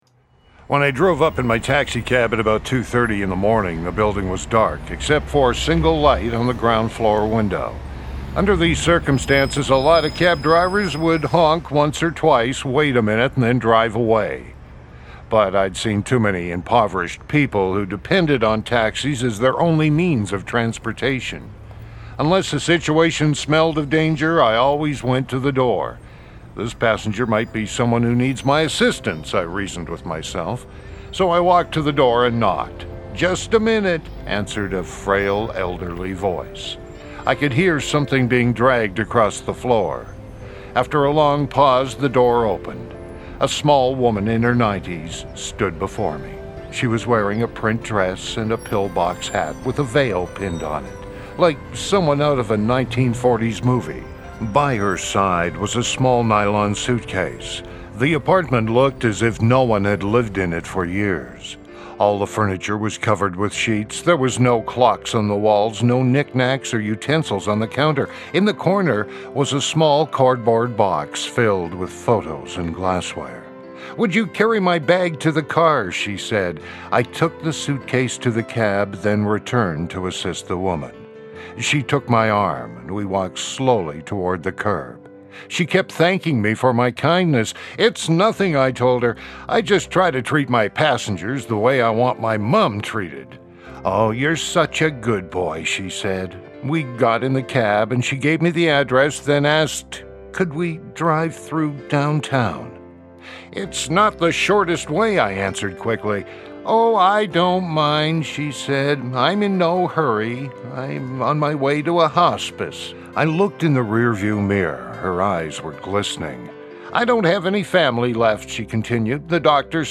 Tags: Voice Advertising Voice Actor Voice Over Media